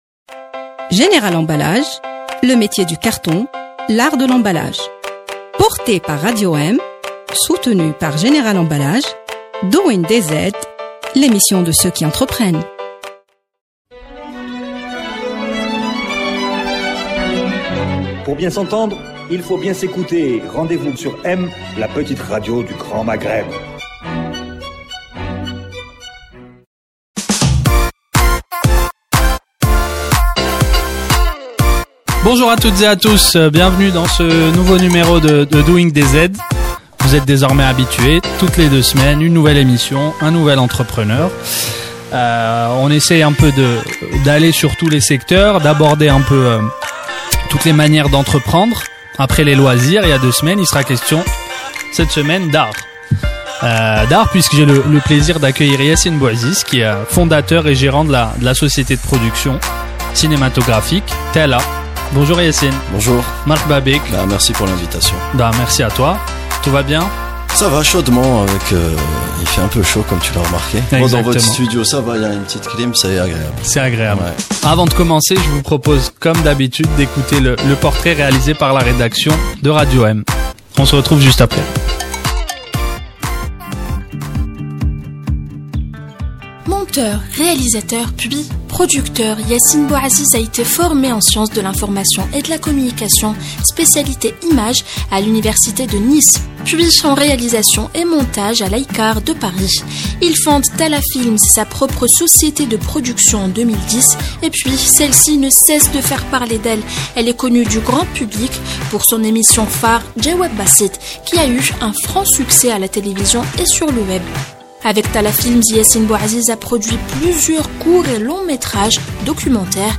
Écoutons cette interview avec ce jeune entrepreneur atypique et charismatique qui nous raconte son parcours.